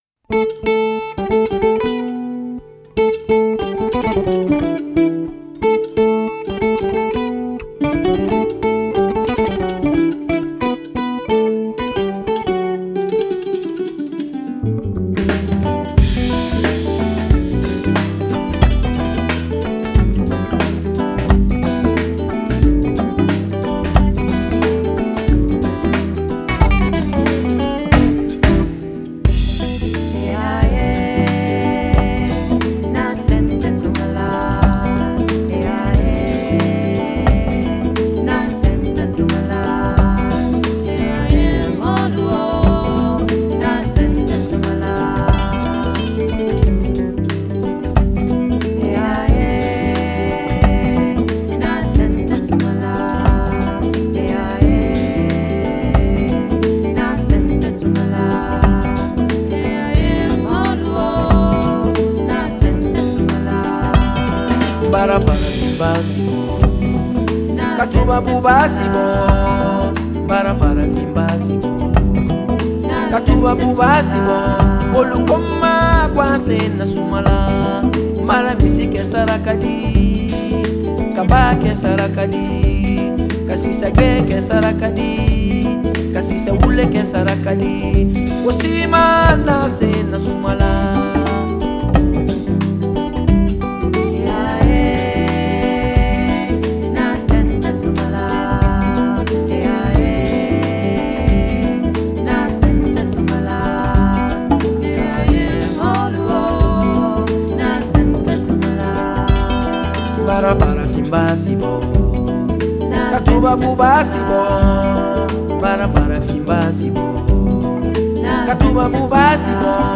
a clever mix of traditional sounds with an urban groove